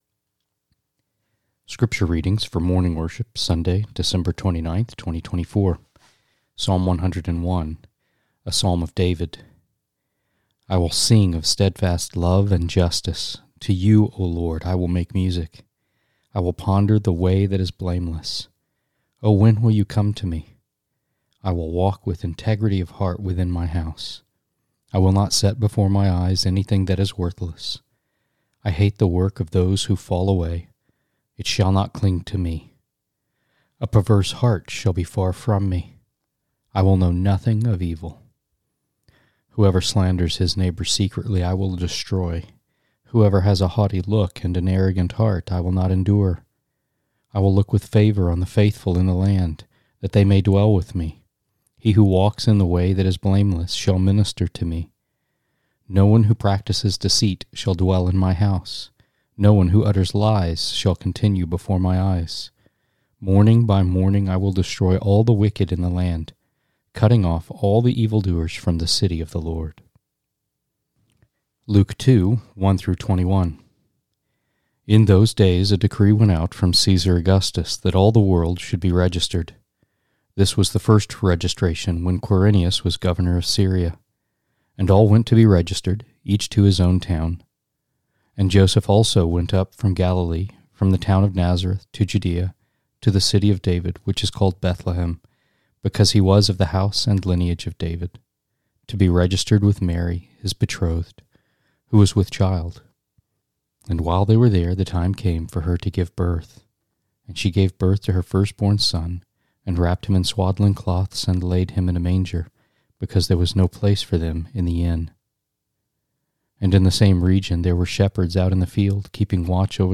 Sermons and Lessons from All Saints Presbyterian Church
Sermons and Lessons from All Saints Presbyterian Church (PCA) in Brentwood, TN.